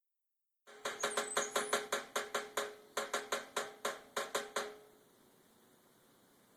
A Yellow Bellied Sapsucker decides that the chimney on our discarded stove in the front yard might be a good place to store acorns…he’s hammering away at it and sounding like a demented drummer.
Yellow Bellied Sapsucker Audio